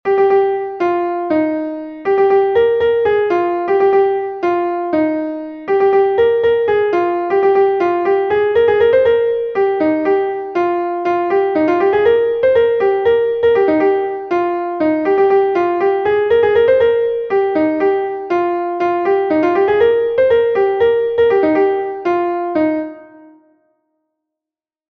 Ton Bale Konk-Leon is a Bale from Brittany